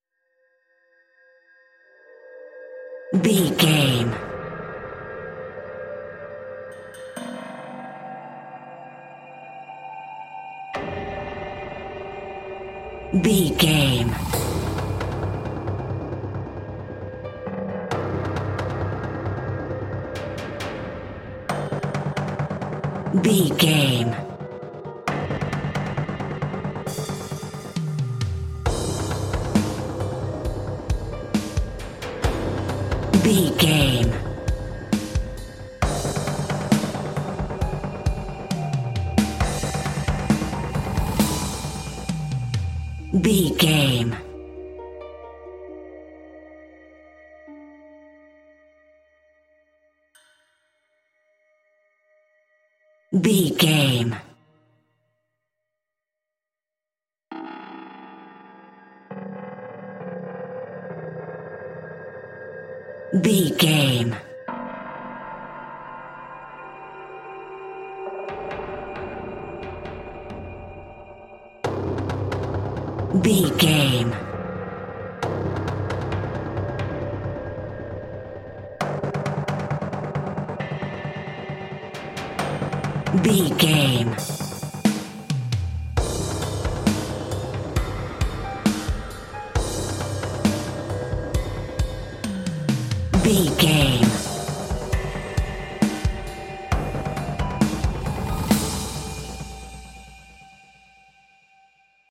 Aeolian/Minor
eerie
haunting
suspense
harp
drum machine